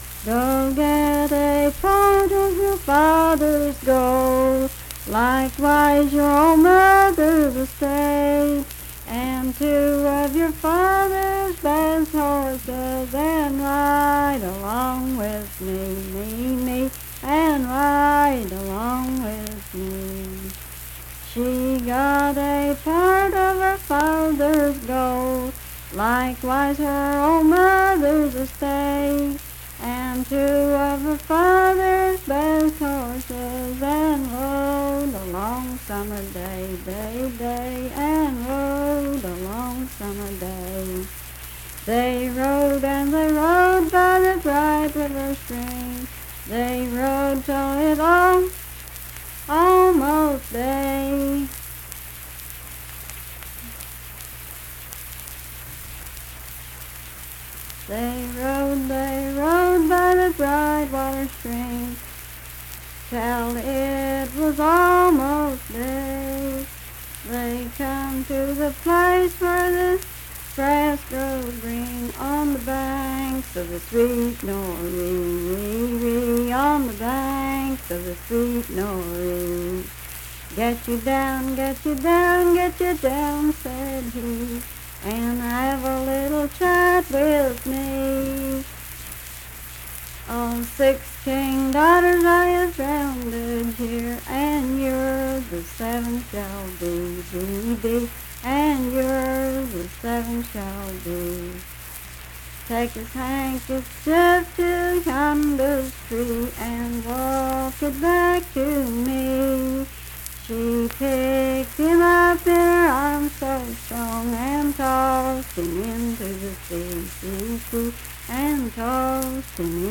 Unaccompanied vocal music
Verse-refrain 7(5w/R).
Voice (sung)
Sutton (W. Va.), Braxton County (W. Va.)